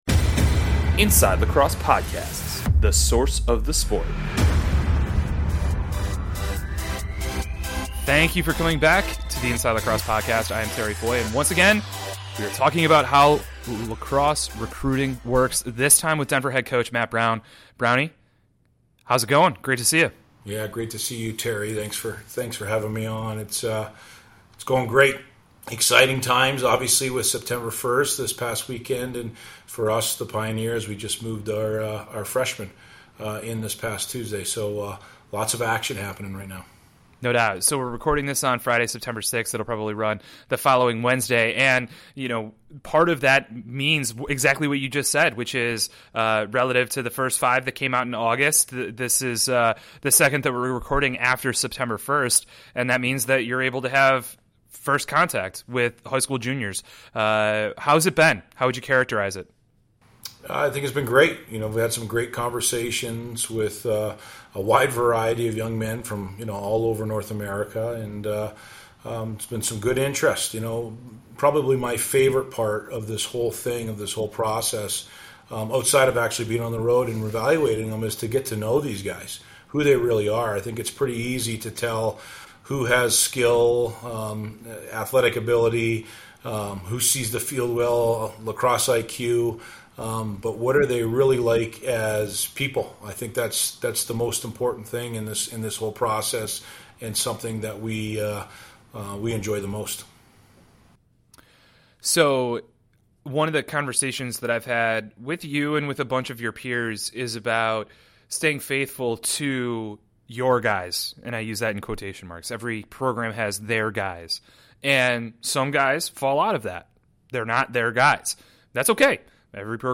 he's interviewing a series of DI men's lacrosse coaches about their process